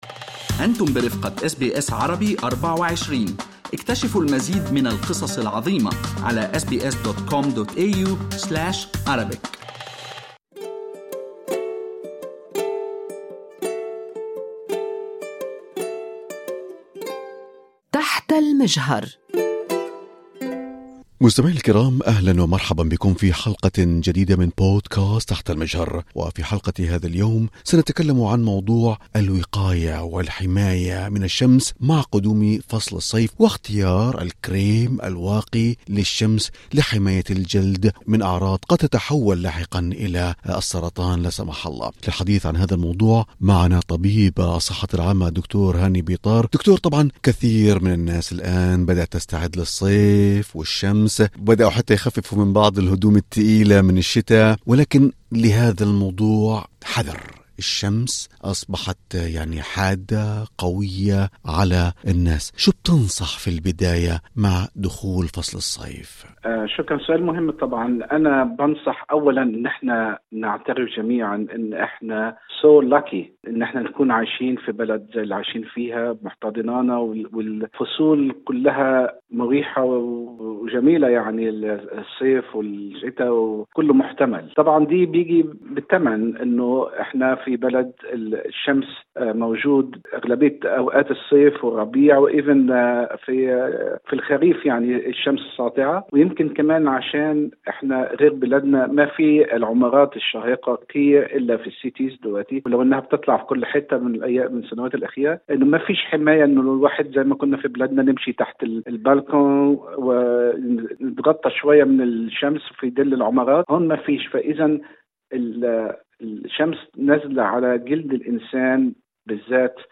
ما فوائد الكريمات الواقية من الشمس والفرق بين الغالي والتجاري منها: طبيب يشرح